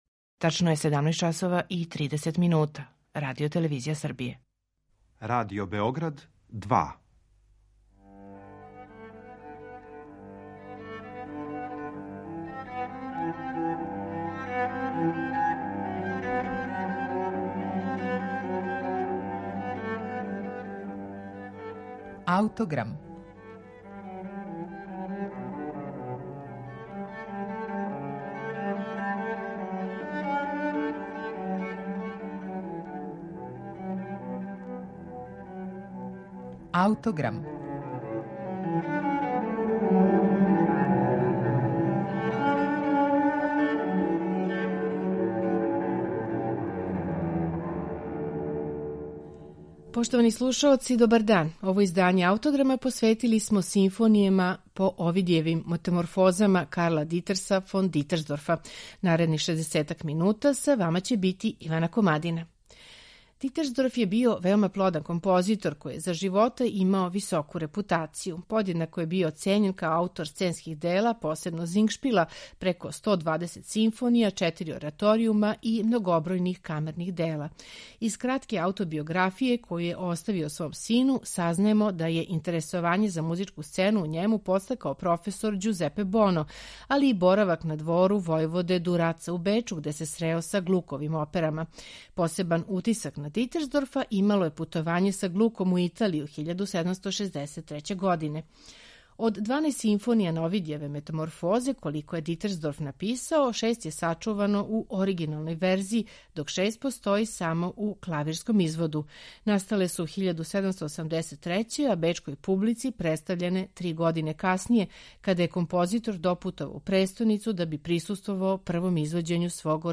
У данашњем Аутограму представићемо прве три симфоније из овог циклуса